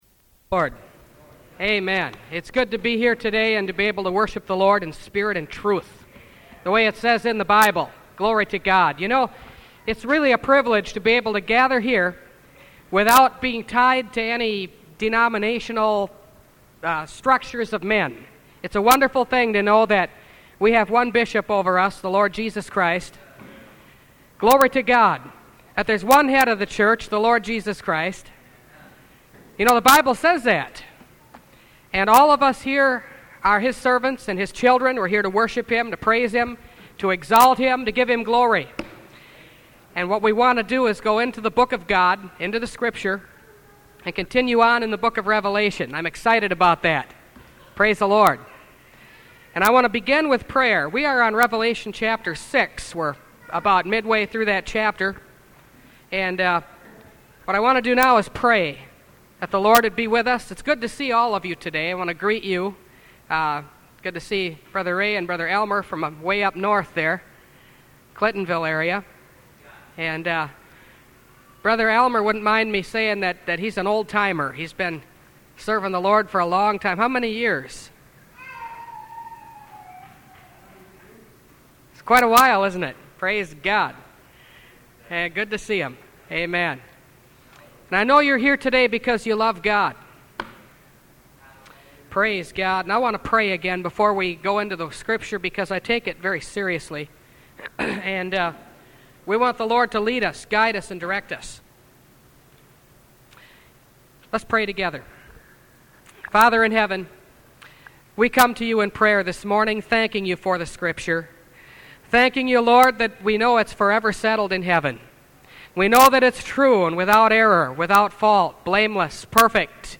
Revelation Series – Part 8 – Last Trumpet Ministries – Truth Tabernacle – Sermon Library